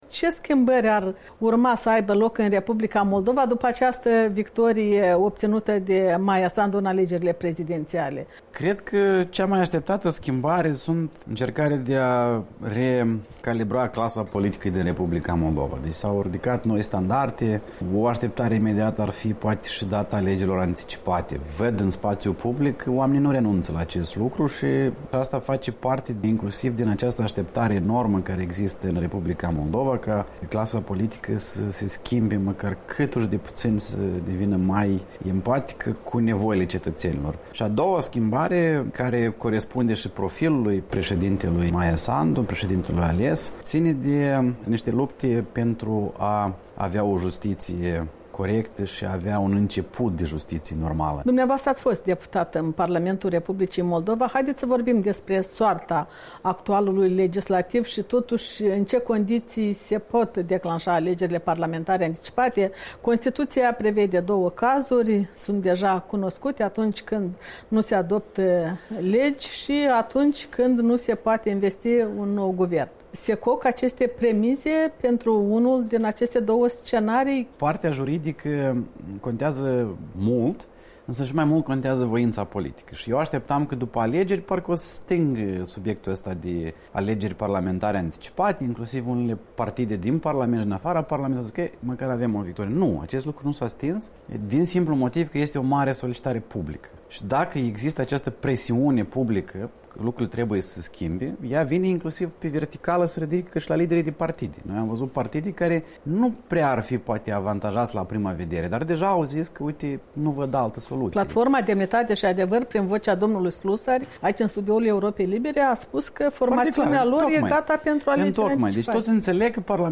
Interviu cu Vadim Pistrinciuc